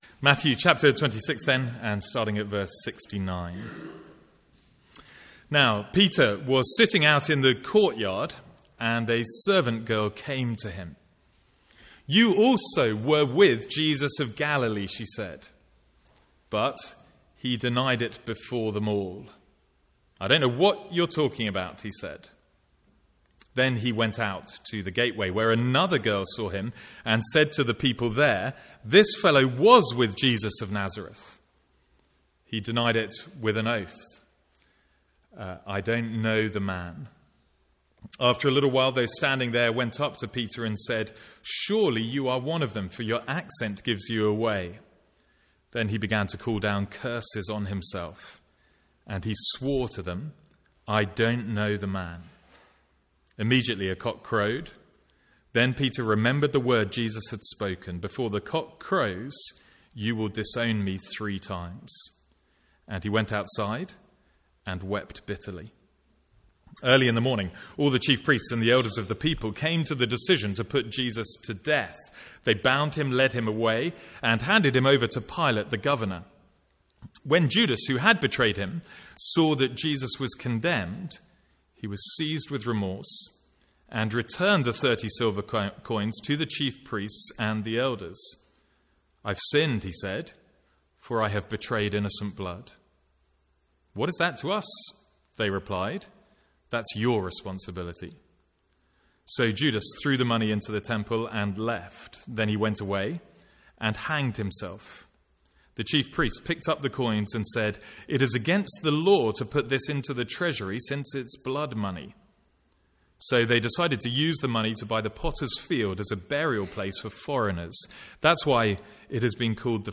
Sermons | St Andrews Free Church
From the Sunday morning series in Matthew.